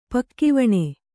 ♪ pakkivaṇe